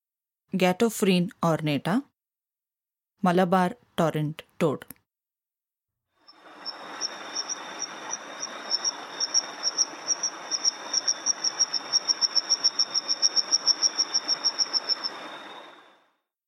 Adult male of G. ornata vocalizing.
The next time you are out there, keep an eye out for the dark frog and heed ear to the tinkling sound- for the frog may have something to say.
Ghatophryne-ornata-Malabar-Torrent-Toad1.mp3